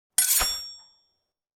SwordSoundPack
SWORD_11.wav